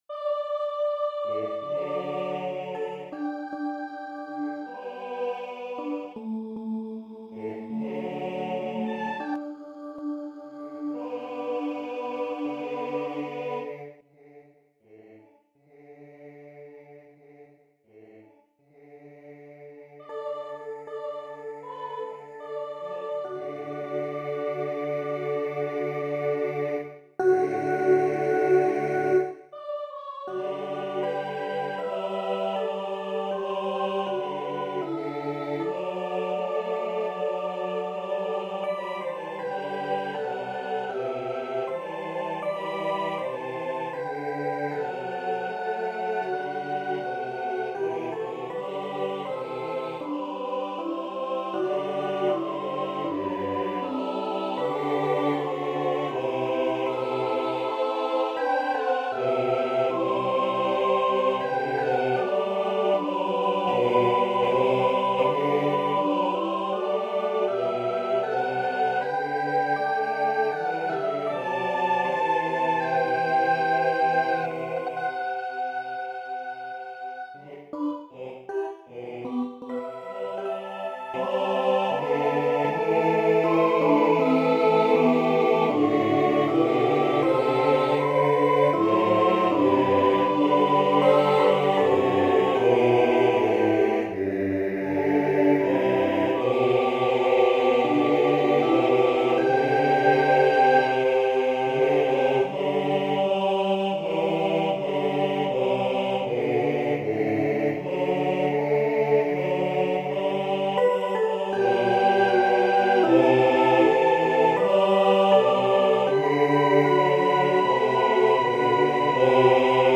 SATB (4 voices mixed). Sacred.